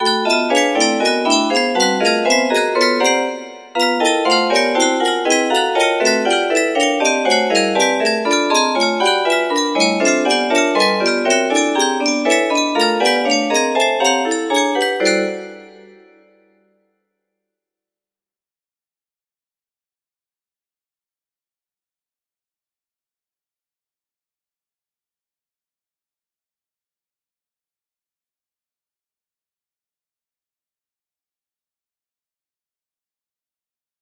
P7 music box melody